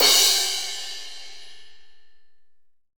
Ew Crash.wav